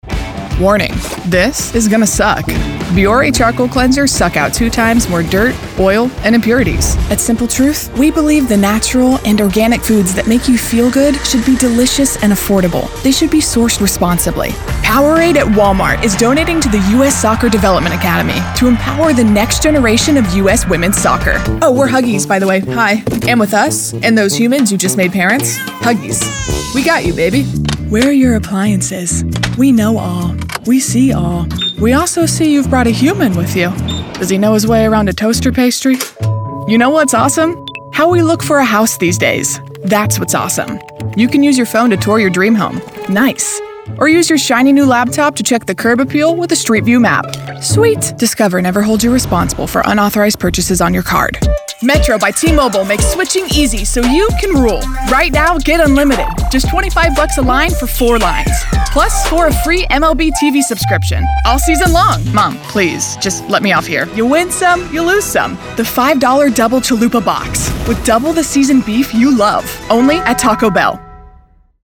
Commerical
English (North American)
COOL & YOUTHFUL VOCAL STYLE
Authentic & Warm